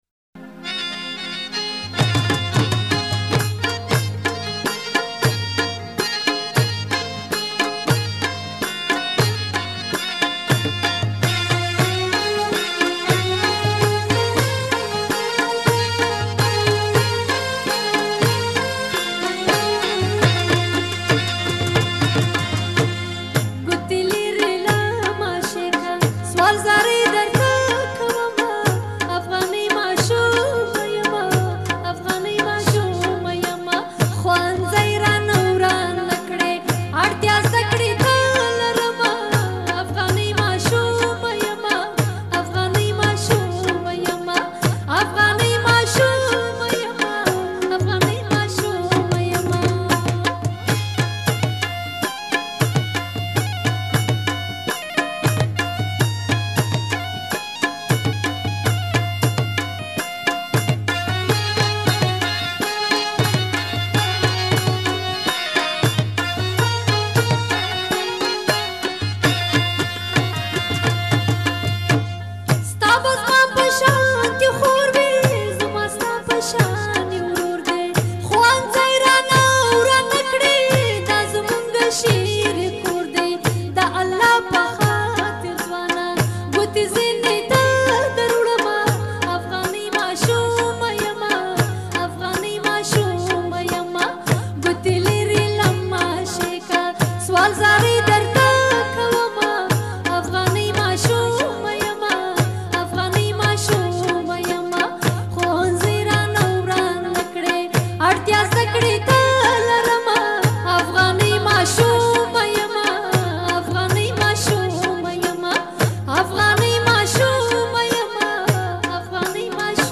ملي سندره